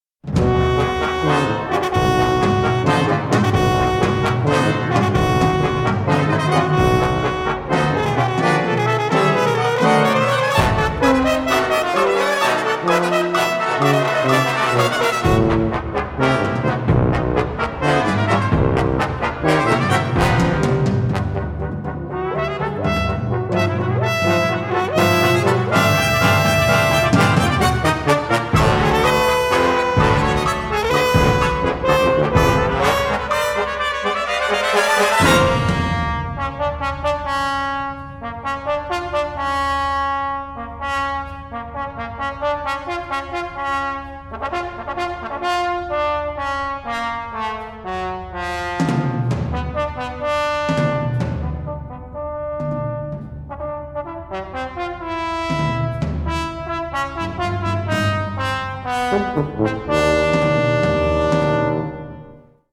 trumpets and flugels
French horn
trombones and euphonium
tuba
percussion and drums